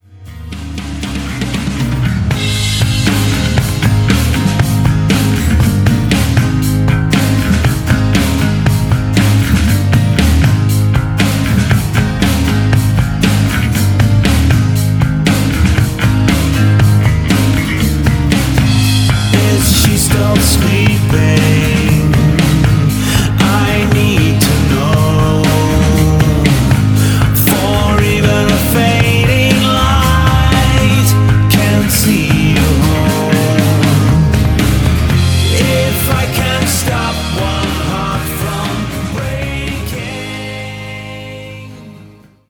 RETRO MODERNIST ALT-METAL